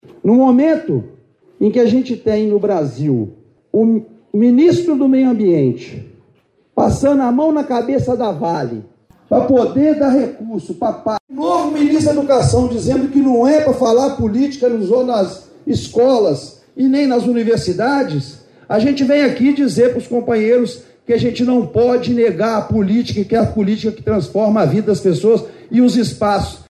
deputado federal Júlio Delgado
botanico-julio-delgado.mp3